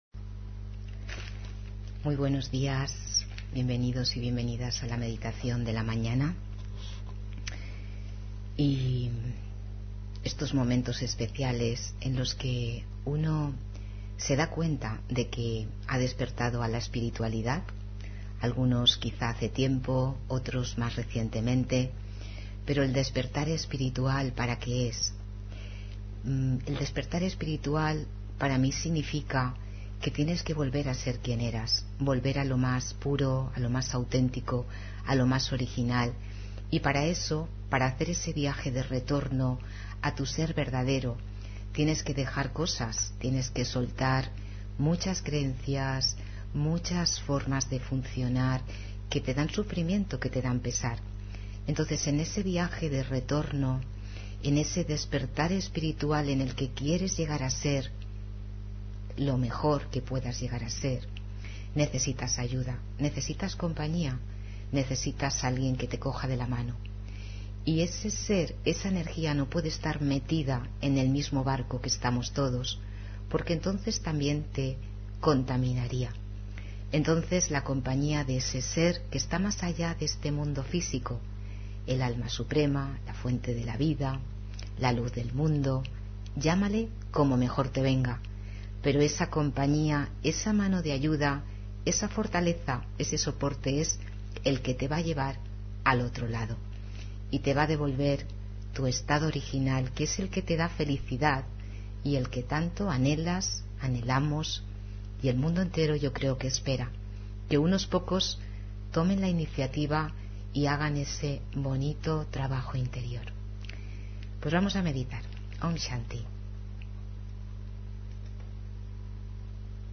Meditación y conferencia: 2024 El año del ángel (14 Enero 2024)